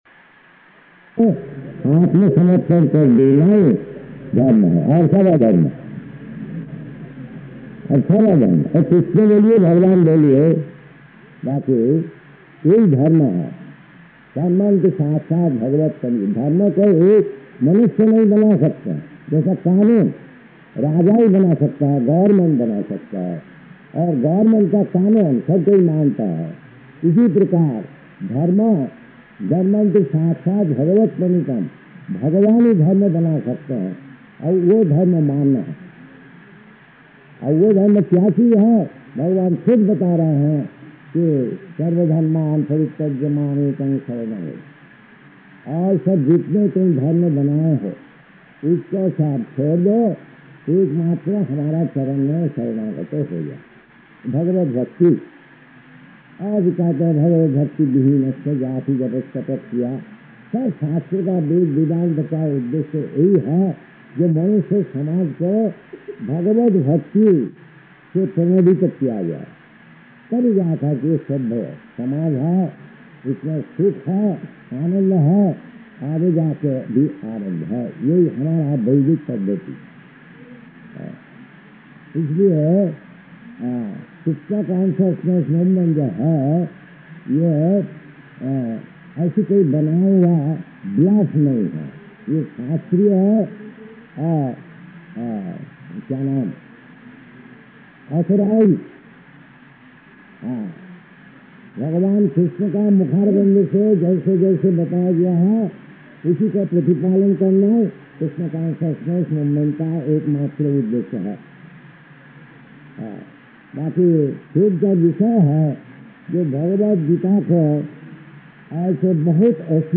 Lecture in Hindi
Location: Surat
Audio file: 701220LE-SURAT.mp3